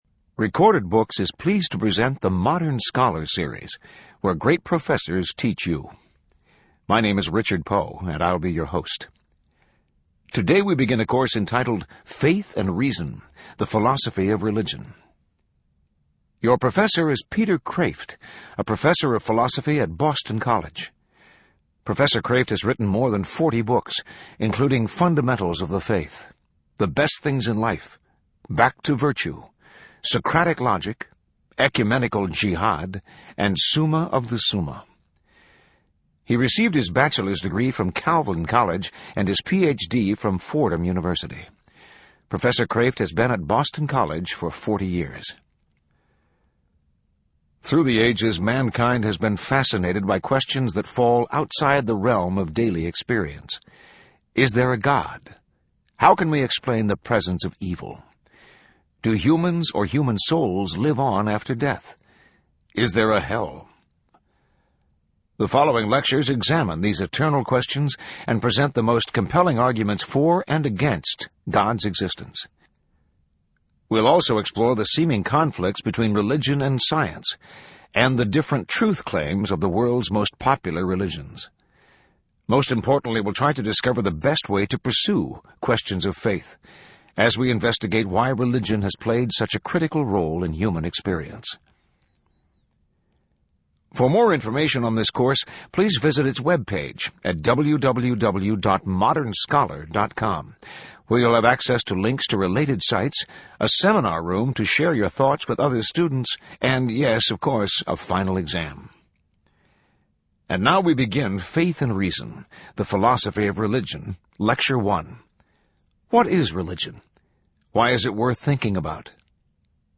In this lecture Professor Kreeft explores what religion is and what questions it tries to answer. He goes over a brief history of philosophical definitions of religion.